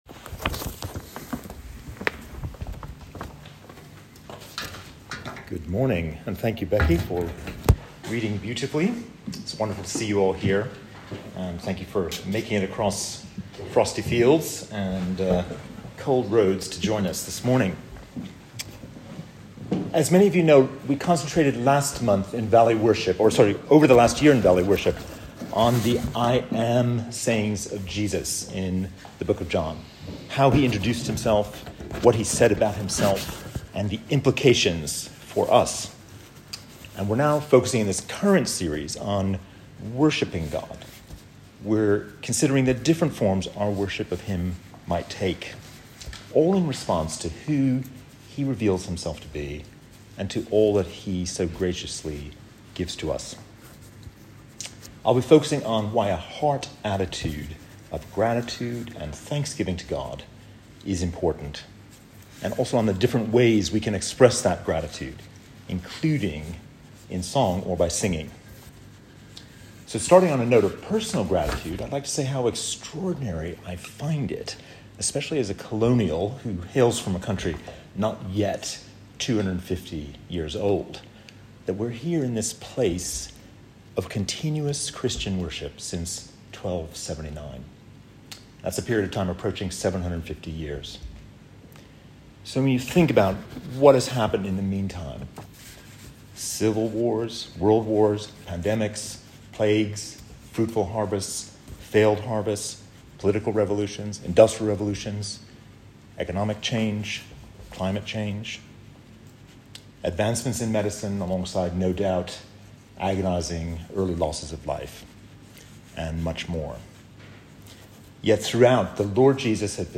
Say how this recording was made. Over 50 people enjoyed a wonderful Valley Worship service yesterday.